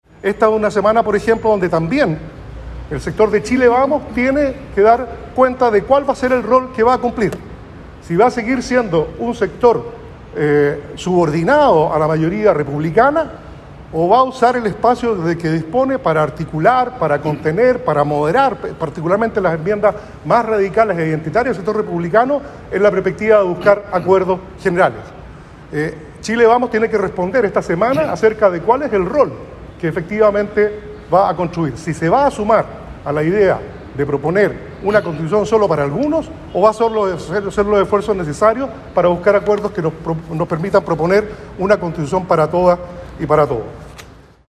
En suma, el consejero oficialista denunció, con un tono más duro, a los representantes republicanos por levantar «bulos» y fake news dentro del proceso, en lugar de «volcarse a un trabajo serio y riguroso de búsqueda de acuerdos»; y a sus pares de Chile Vamos por solo entregar «afirmaciones contradictorias» y «propuestas confusas» sin voluntades reales.